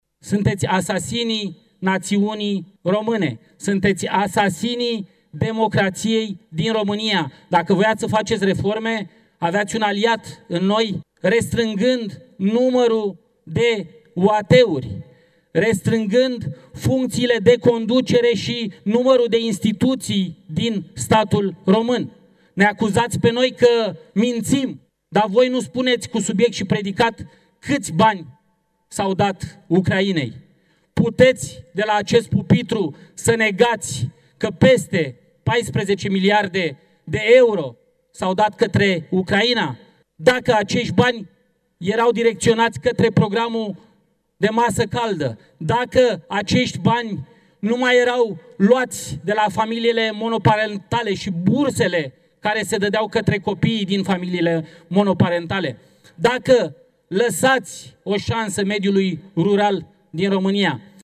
Senatul şi Camera Deputaţilor s-au reunit, duminică, în şedinţă comună, pentru a dezbate şi vota cele patru moţiuni de cenzură ale Opoziţiei depuse după angajarea răspunderii Guvernului pentru pachetul al doilea de măsuri privind reducerea deficitului bugetar.